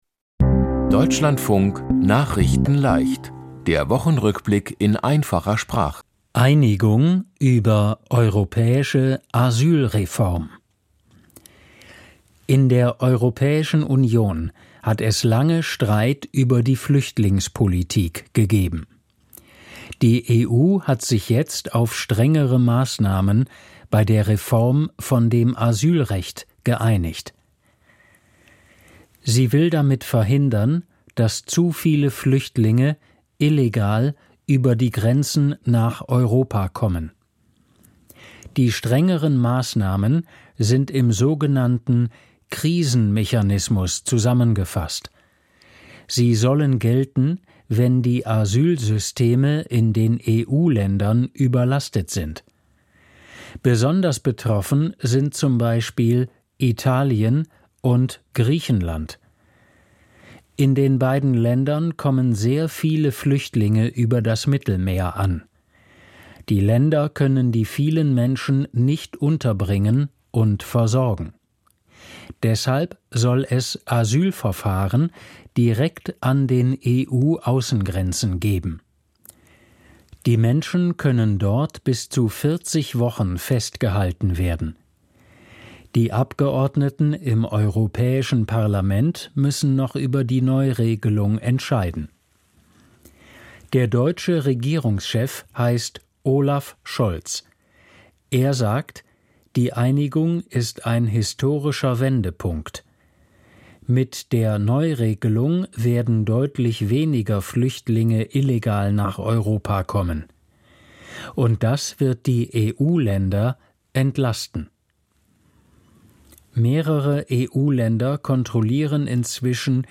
Die Themen diese Woche: Einigung über europäische Asyl-Reform, EU-Außen-Minister in Kiew, Tag der Deutschen Einheit, Sprecher vom US-Repräsentanten-Haus gestürzt, der Norweger Jon Fosse bekommt Literatur-Nobel-Preis und Fußball-WM 2030 auf 3 Kontinenten. nachrichtenleicht - der Wochenrückblick in einfacher Sprache.